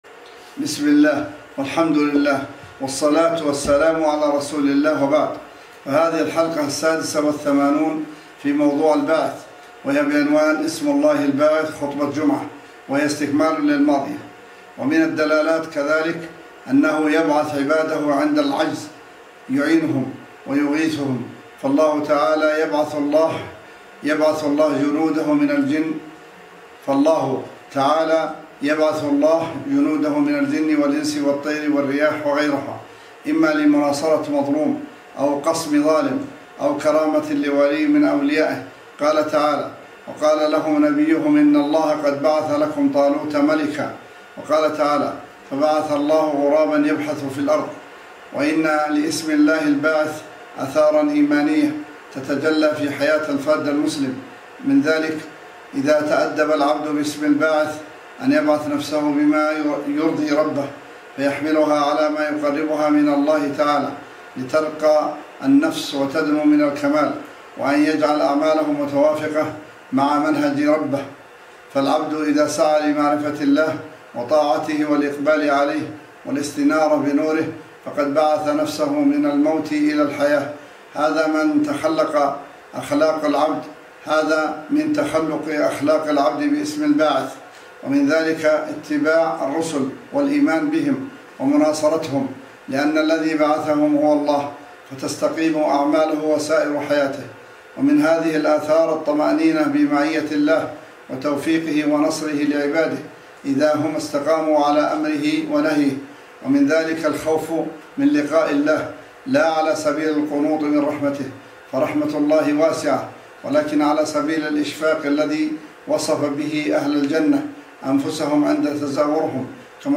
الحلقة السادسة والثمانون في موضوع (الباعث) وهي بعنوان :           * اسم الله الباعث – خطبة جمعة :